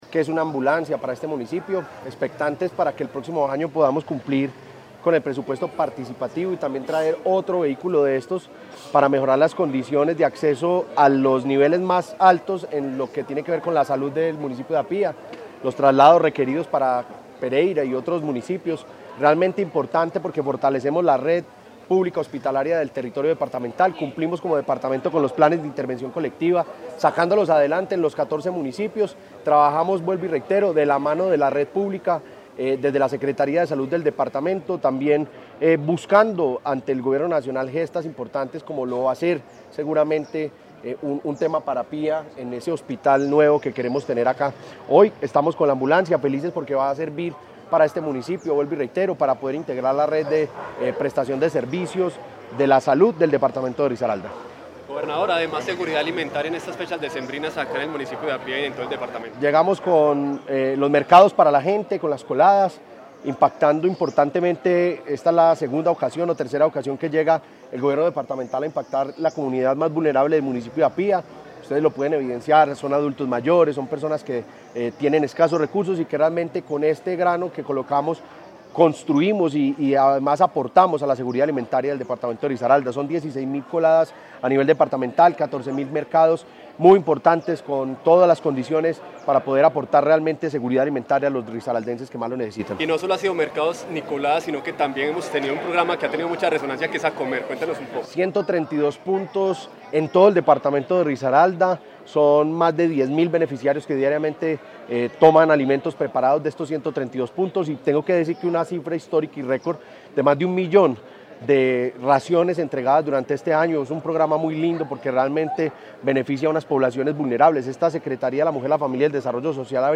Sobre el vehículo destinado al trasporte hospitalario, que beneficiará a más de 12 mil habitantes del municipio, el gobernador Juan Diego Patiño expresó: